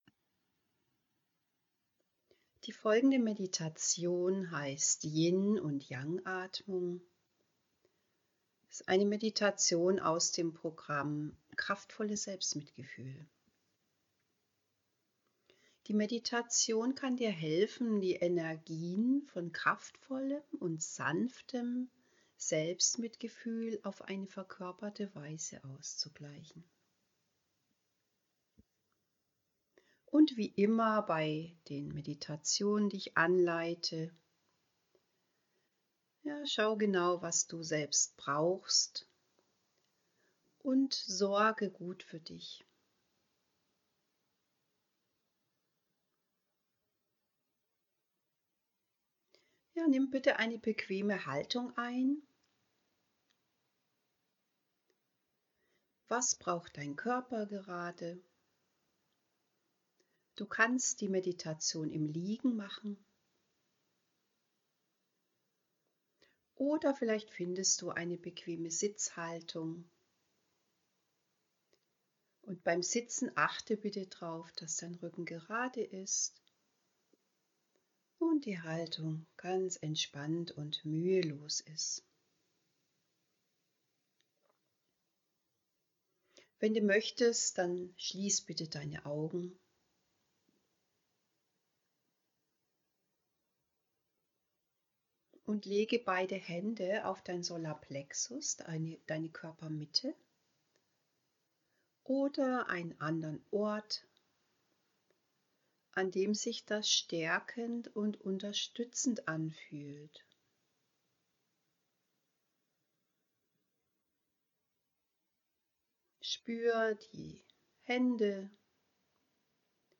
Meditation: Yin und Yang Atmung